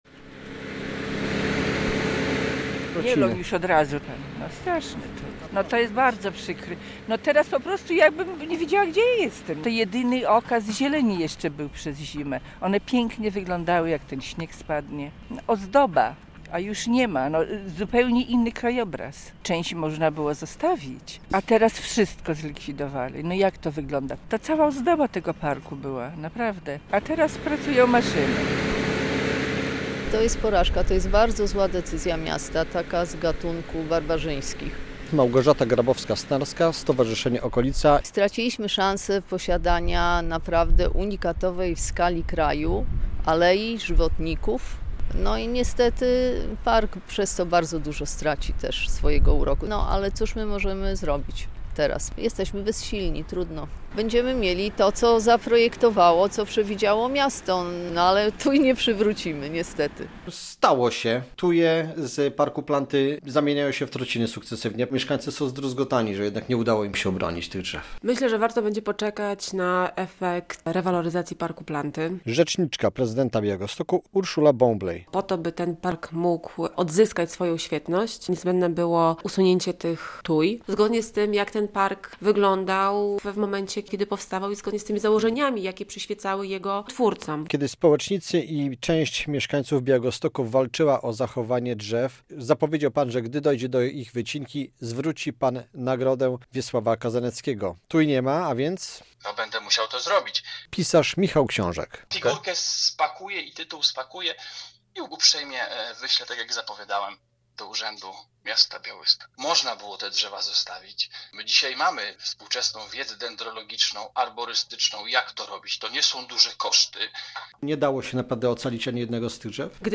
Blisko stuletnie tuje z białostockiego Parku Planty zamieniają się w trociny. W środę (8.01) od rana trwa usuwanie i mielenie drzew z Alei Zakochanych, które rosły kilkudziesięciometrowym szpalerem w sąsiedztwie fontanny.